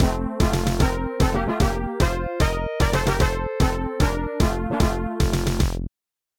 as a midi